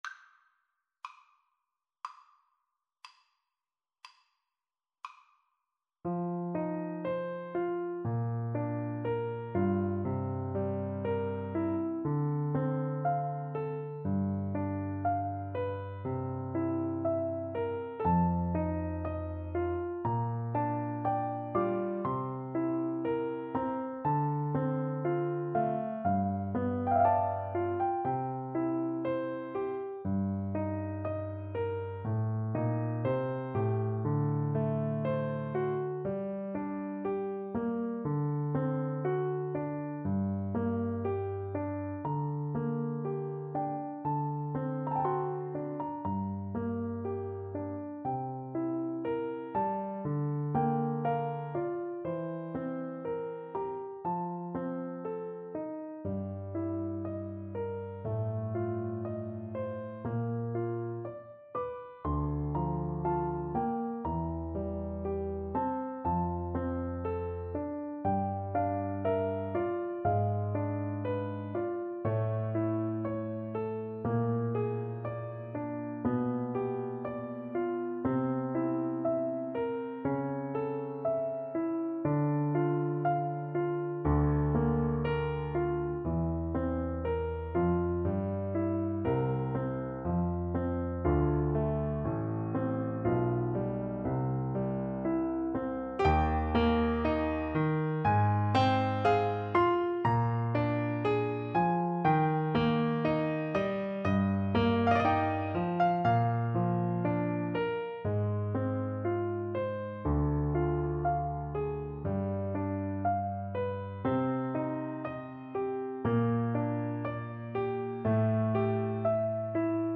~ = 60 Largo
Classical (View more Classical Violin Music)